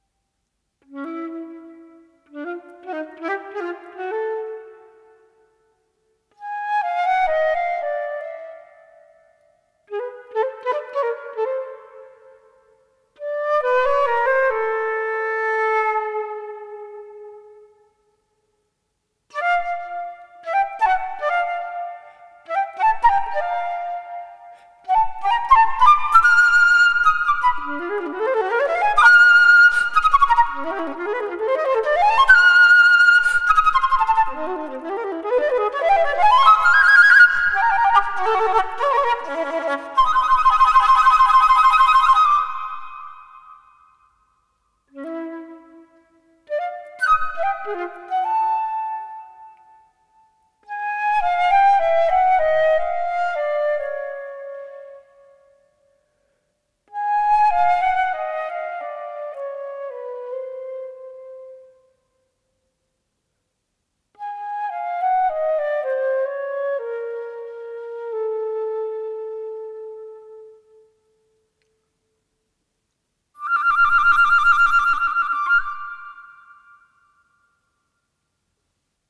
Камерно-инструментальная музыка